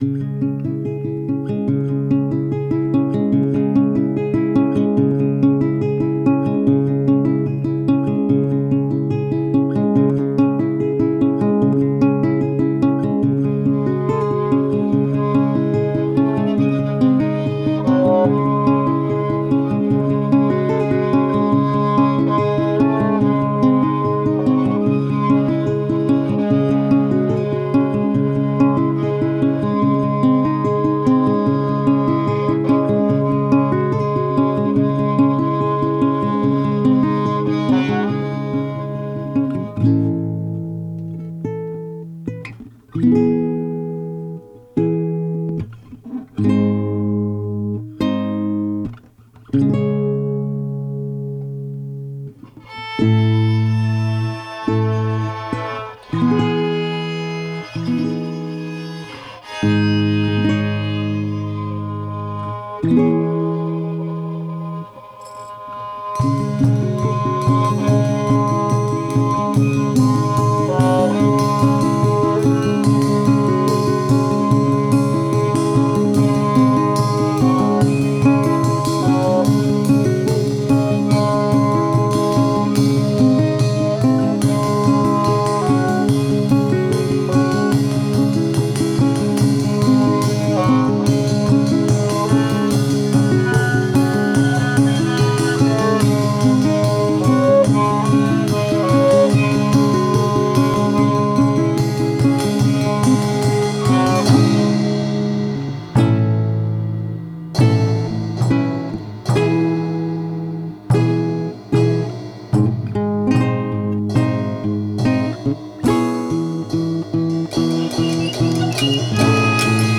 タグ: 映画、ギター、悲しい、ドラマチック